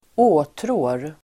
Uttal: [²'å:trå:r]